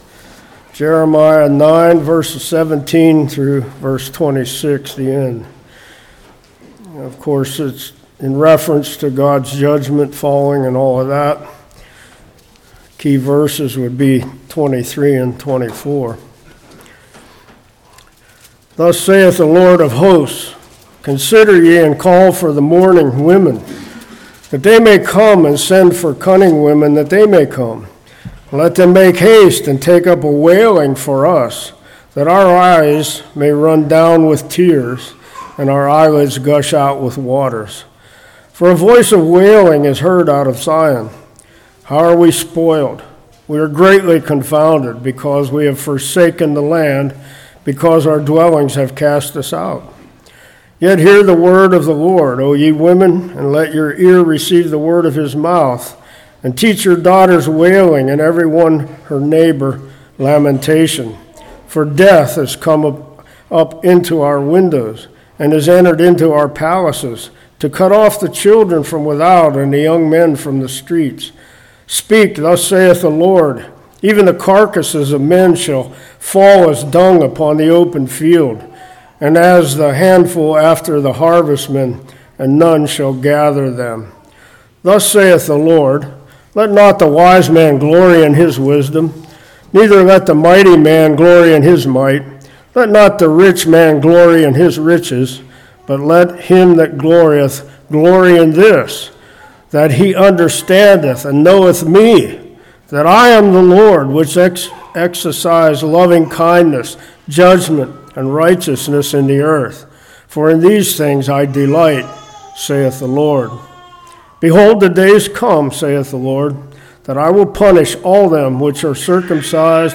Jeremiah 9:19-26 Service Type: Morning Glorying Apart From God Man’s Wisdom Man’s Might Riches « Deacon Confirmation Moses